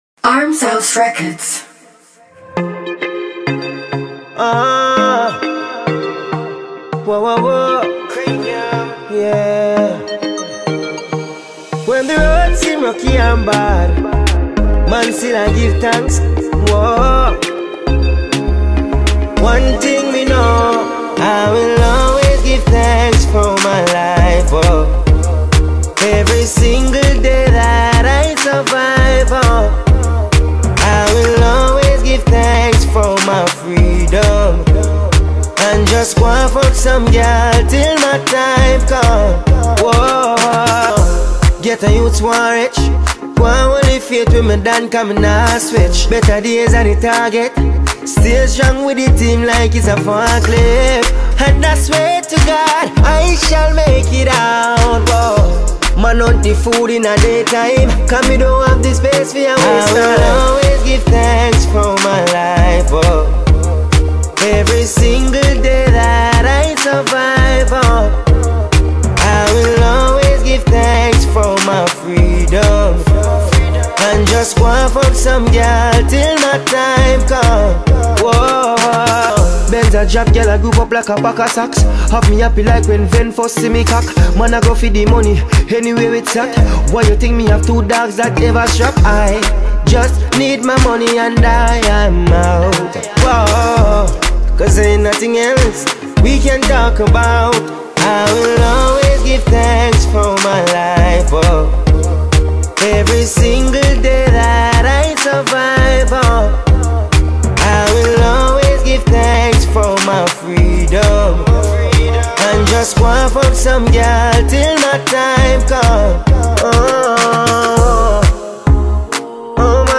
Reggae/Dancehall